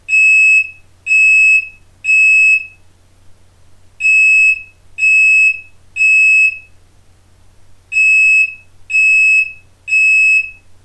Aus diesem Grund haben wir die Warnmelder Alarme zahlreicher aktueller Modelle für Sie aufgezeichnet.
innogy-smarthome-wsd-2-funkrauchmelder-alarm.mp3